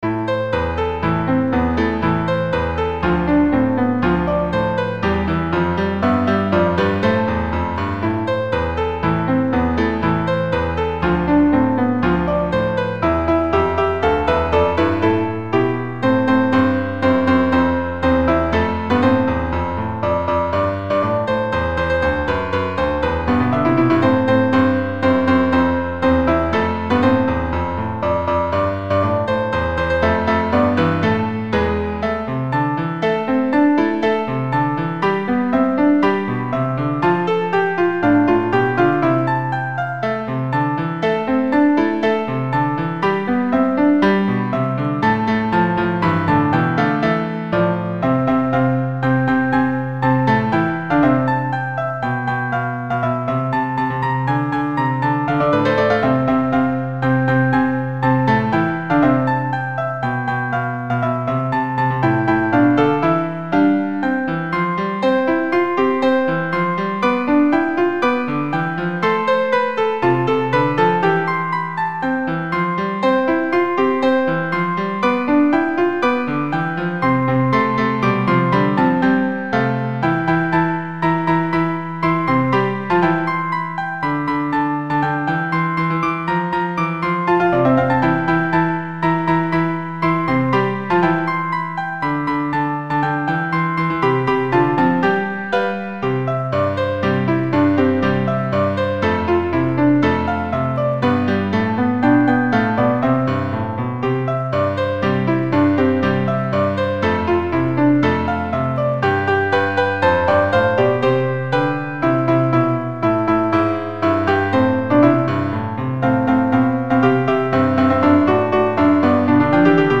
Melody Assistant Arrangements